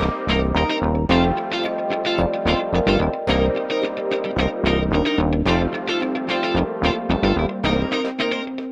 30 Backing PT3.wav